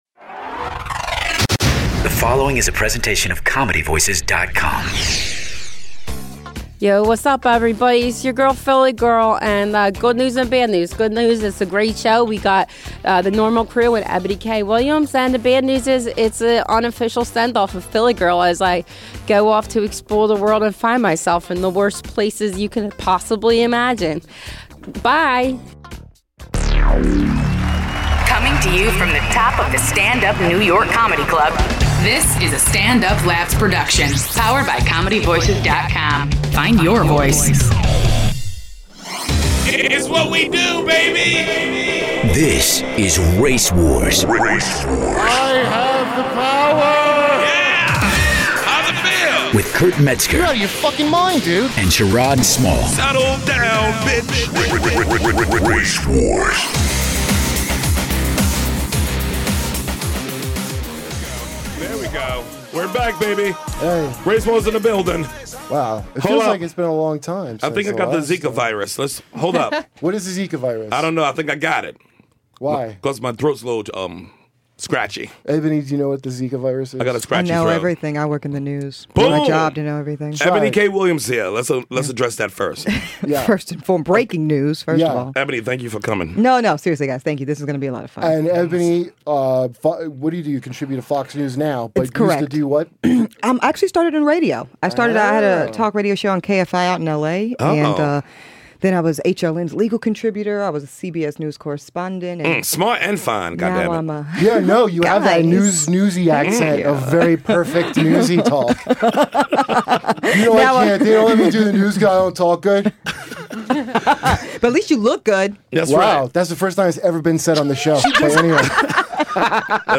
Eboni K. Williams of FOX News is in the studio this week to talk about Bill Cosby, the Zika virus, and the presidential race.